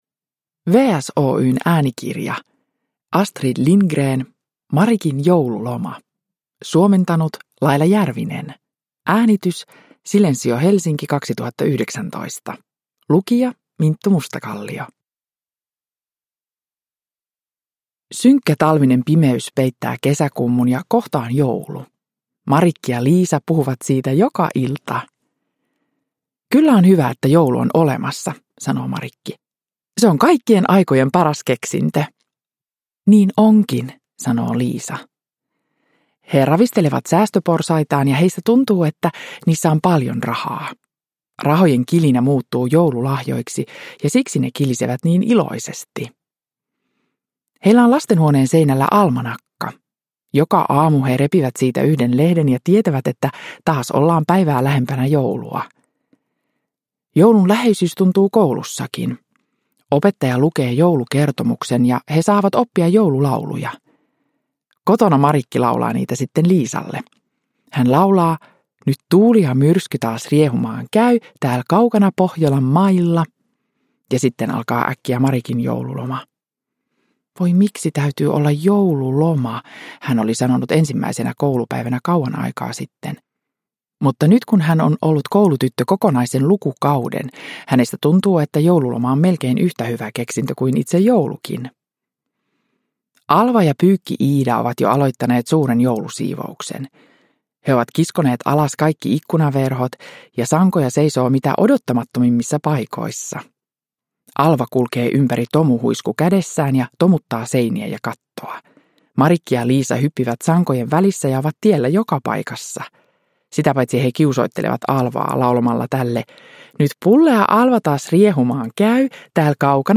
Marikin joululoma – Ljudbok – Laddas ner
Uppläsare: Minttu Mustakallio